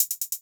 ClosedHH SwaggedOut 1.wav